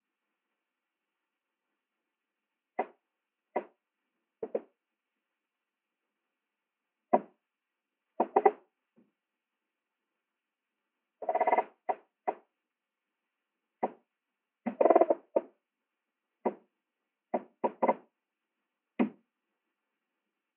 picto longévité animaux marins picto alimentation animaux marins picto son animaux marins